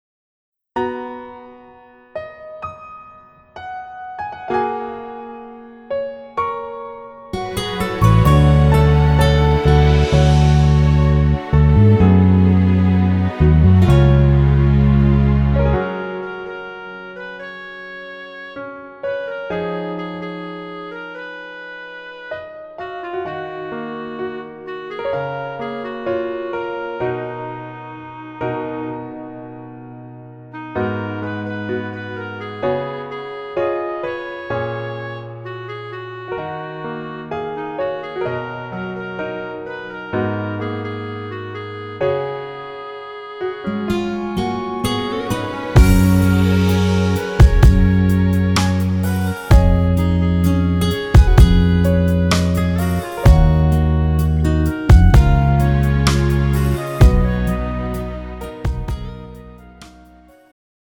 음정 여자-1키
장르 축가 구분 Pro MR